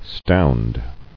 [stound]